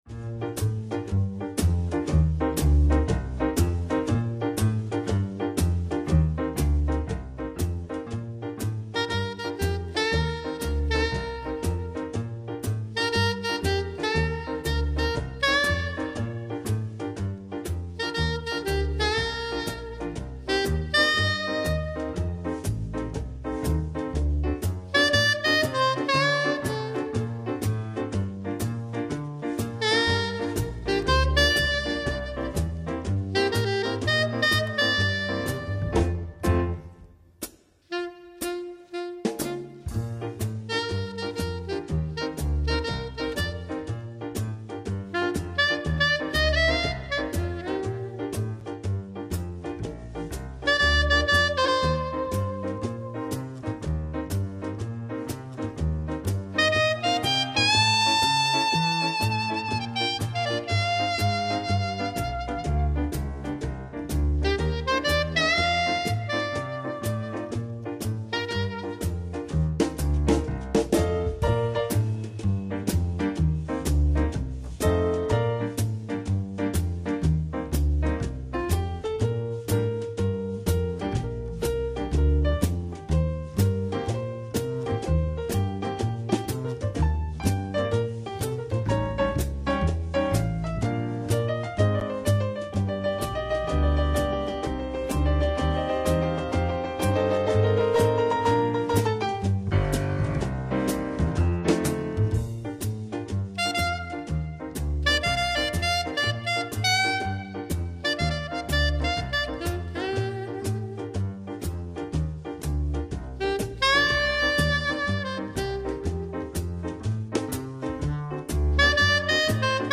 TOUT LE TALENT D’UN SAXOPHONISTE
Saxophones Sopranos :